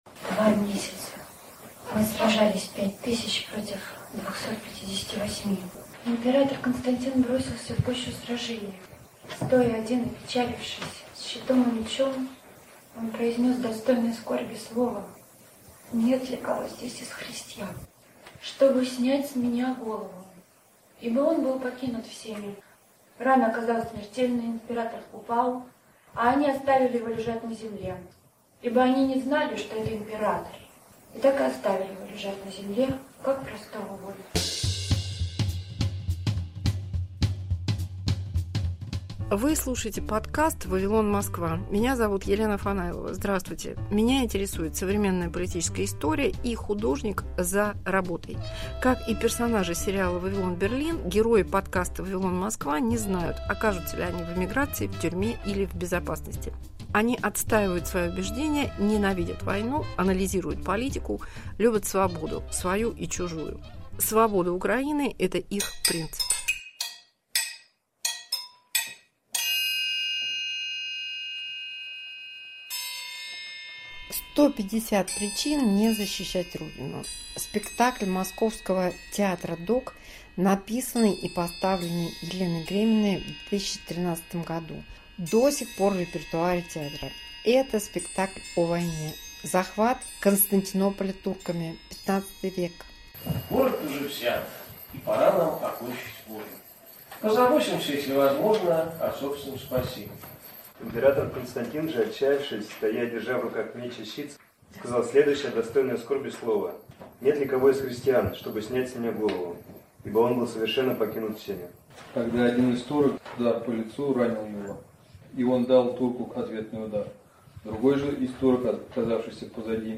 Спектакль Театра.doc: история воинов и предателей, рассказанная женщинами